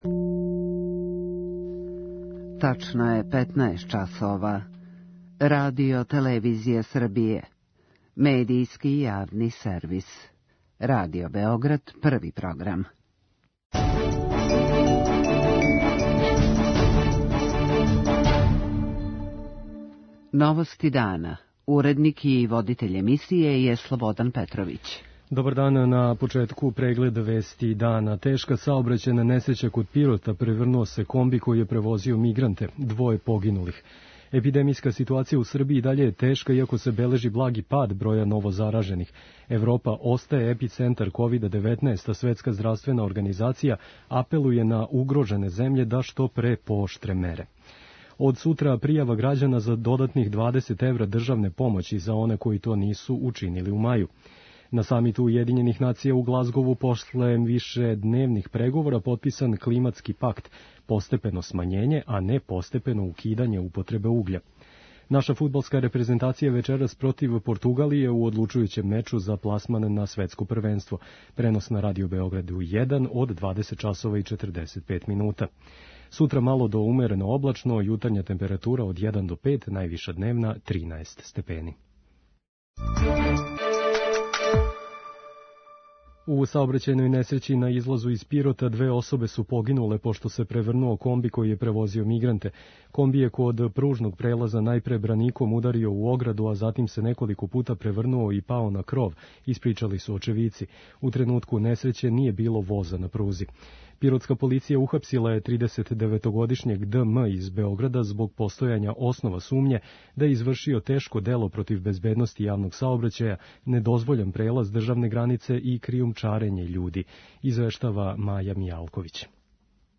У пиротској болници збринуто је двадесеторо повређених, од којих је 19 малолетно. преузми : 6.48 MB Новости дана Autor: Радио Београд 1 “Новости дана”, централна информативна емисија Првог програма Радио Београда емитује се од јесени 1958. године.